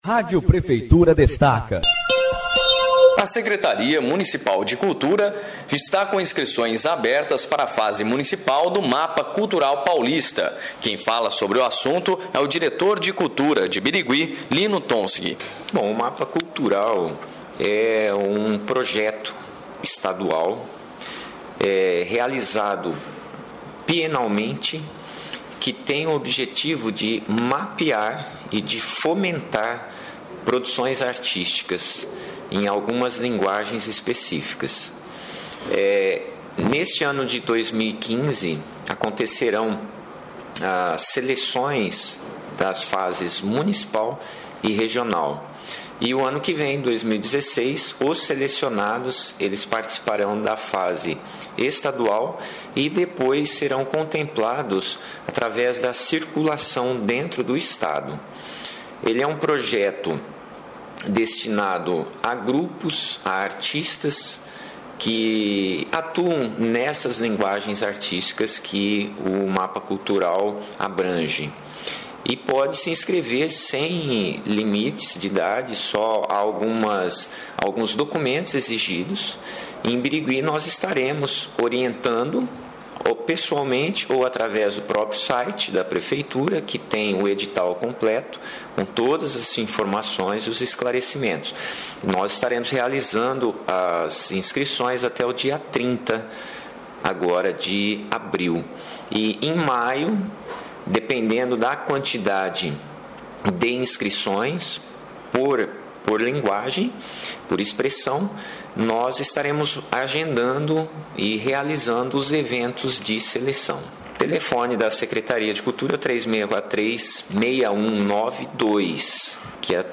A Rádio Prefeitura falou com o diretor de Cultura, Lino Tonsig, sobre o Mapa Cultural Paulista.